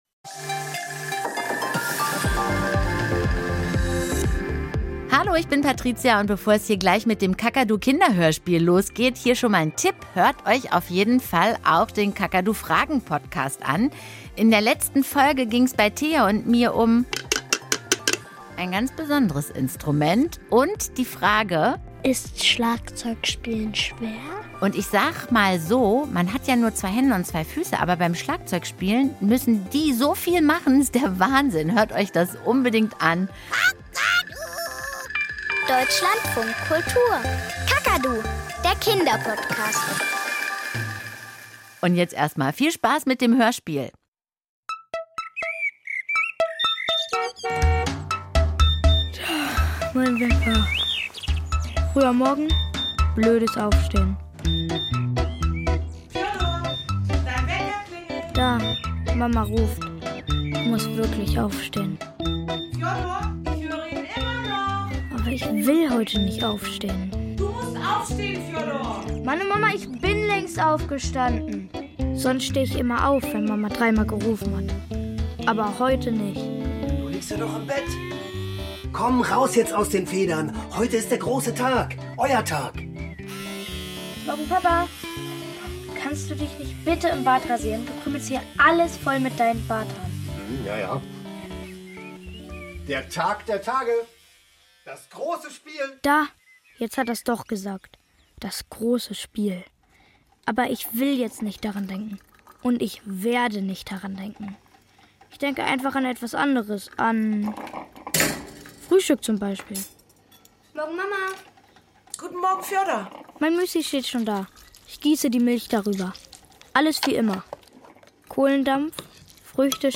Kinderhörspiel - Der Fußballgott